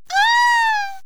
khanat-sounds-sources/_stock/sound_library/voices/death/moans/princess_die3.wav at main
princess_die3.wav